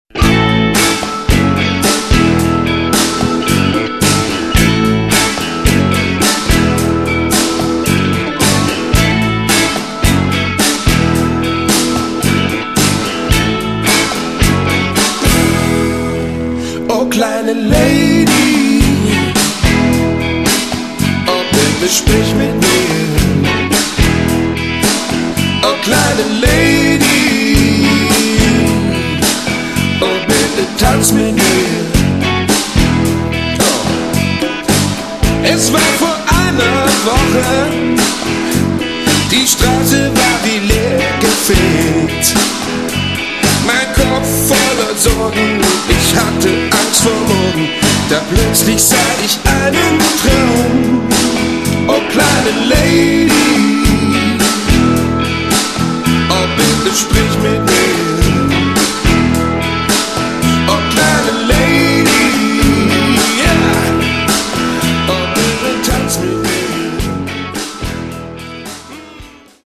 Git.und Voc.
Dr., Chor, Bongolein, klappern & rascheln
elektroorgeln
aufgenommen, gemischt und produziert